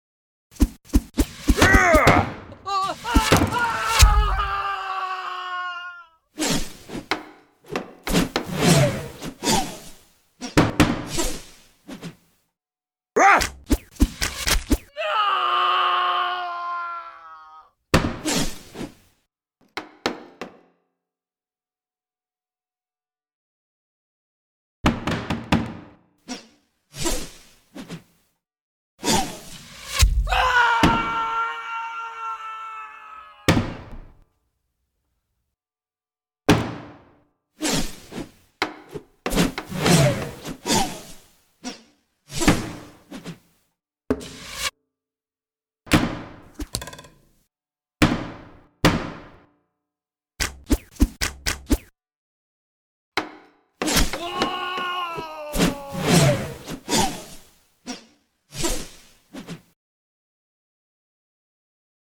The SoundScape at Caerphilly Castle is installed along one of the battlements. Activated via PIR as visitors walk into the area 4 speakers come to life with the sounds of battle all along the defences.